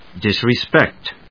音節dis・re・spect 発音記号・読み方
/dìsrɪspékt(米国英語), ˌdɪsrɪˈspekt(英国英語)/